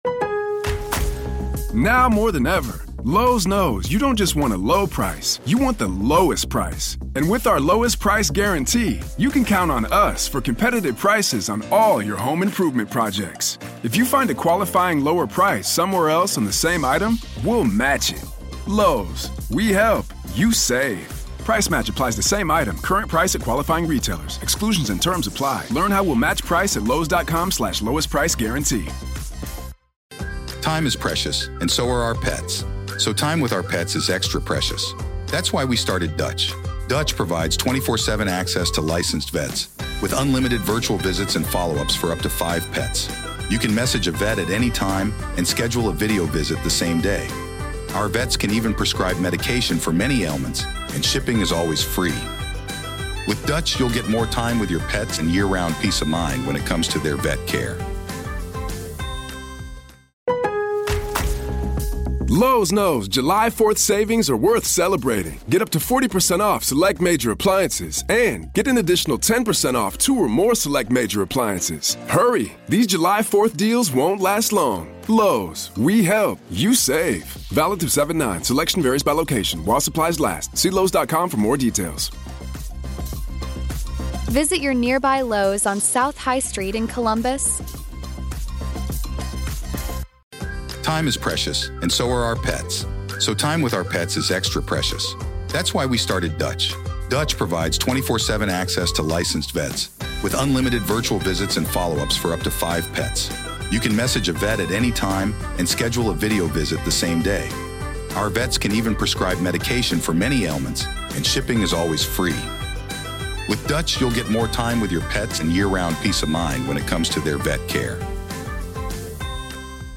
Today, in Part One of our conversation, we discuss the dark and fascinating world of dreams